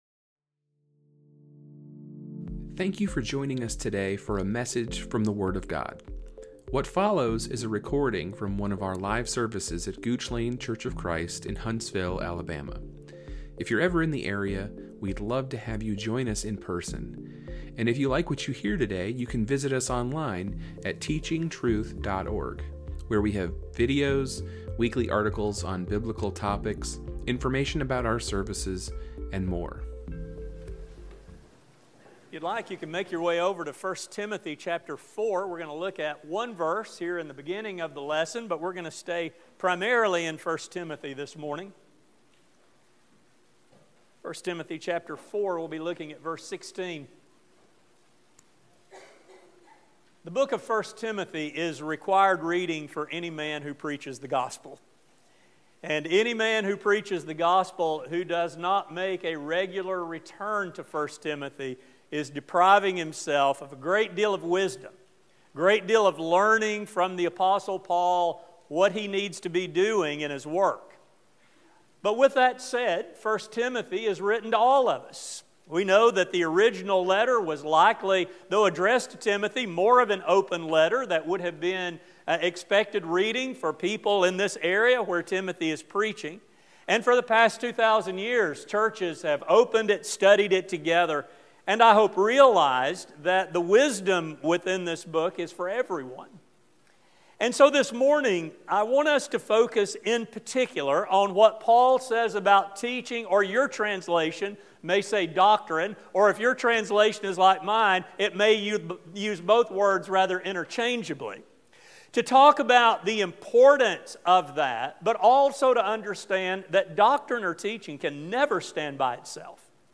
This sermon will explore the intertwined nature of life and teaching (doctrine) not only in the life of a young preacher like Timothy, but in the lives of all who seek to be faithful children of God.